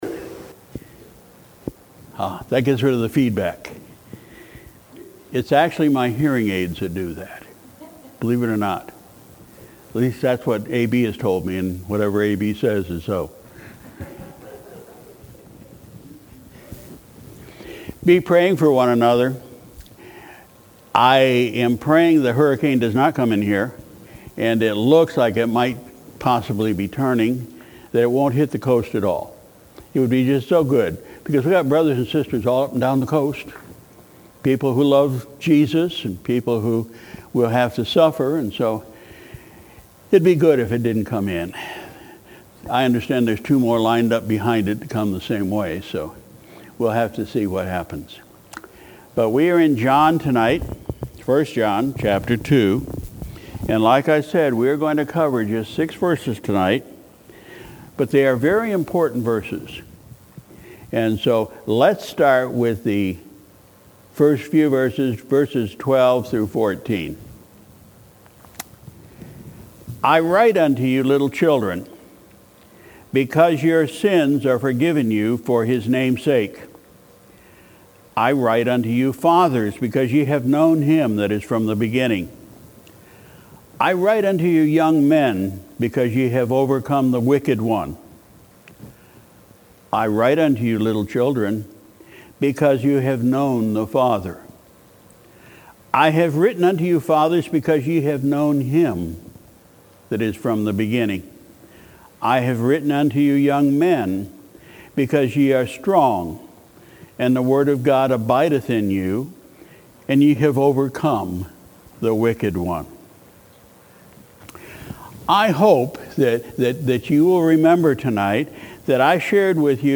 Sunday, September 9, 2018 – Evening Service